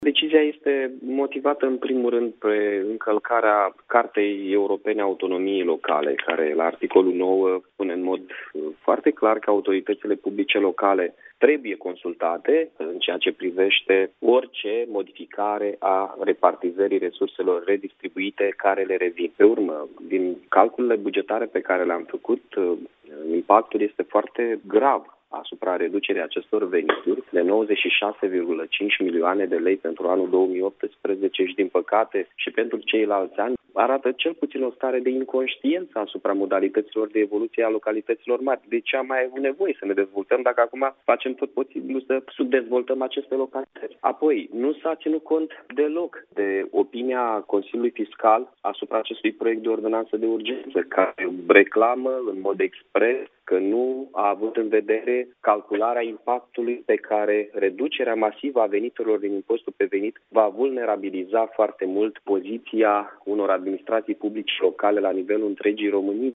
Primarul Mihai Chirica a declarat pentru Radio Iaşi că prin adoptarea acestui act normativ se încalcă un cadru de legalitate privind autonomia locală.